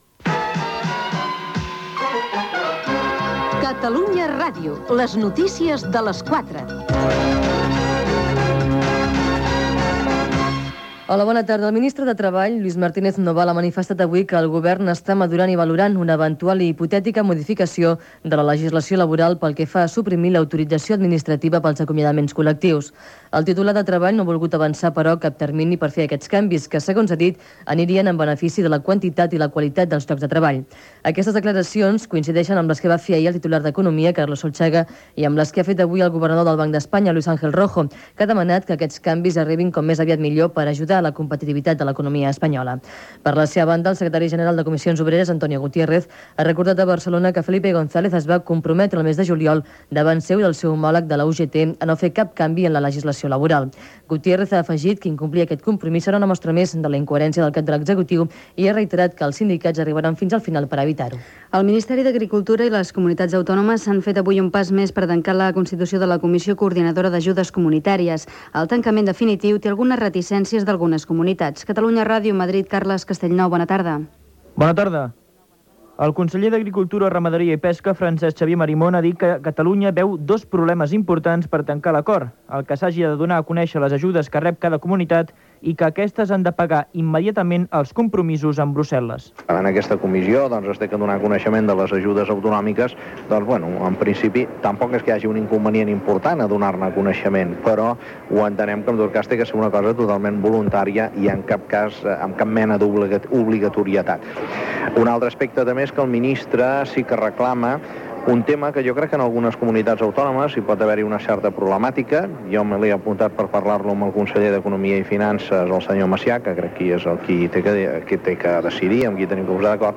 Careta del programa, declaracions del ministre del Treball, ajudes agrícoles, vols Barcelona Buenos Aires, empresa Línea Catálogo, Sud-Àfrica, esports, careta del programa
Informatiu